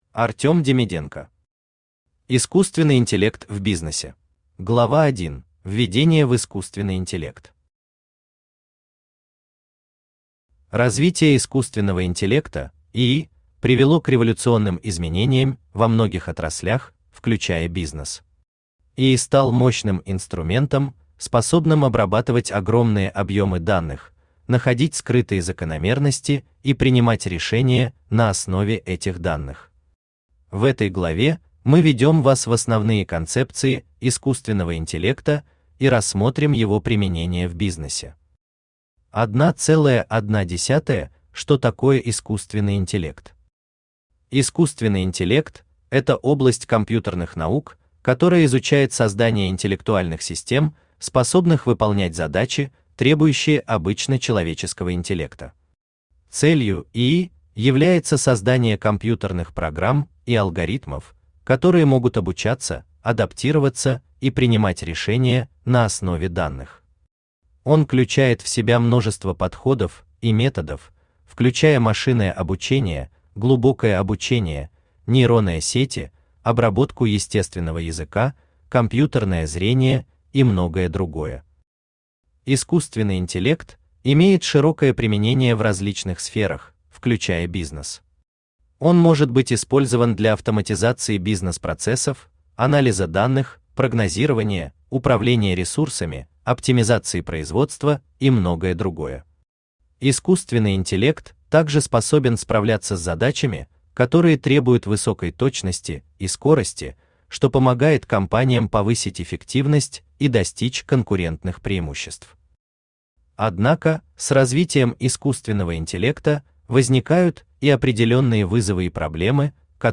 Aудиокнига Искусственный интеллект в бизнесе Автор Артем Демиденко Читает аудиокнигу Авточтец ЛитРес.